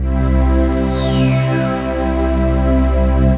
DM PAD2-75.wav